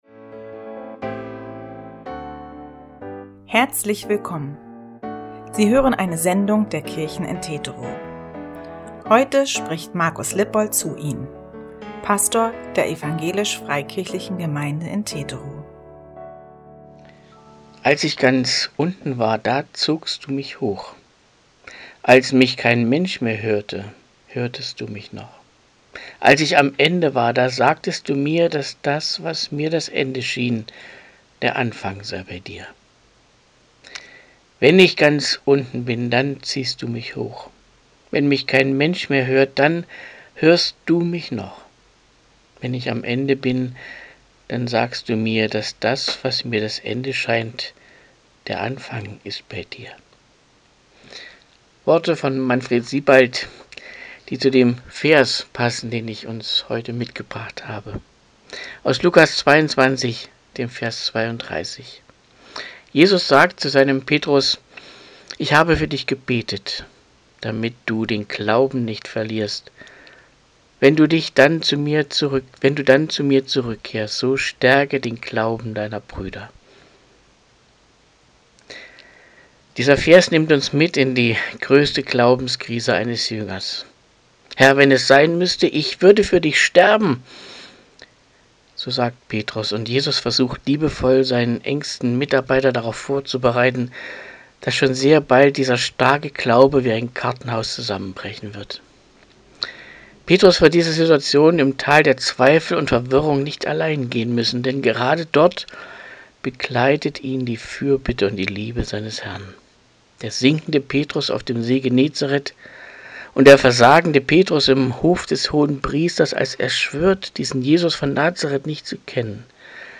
Audio-Andacht vom 19.06.2022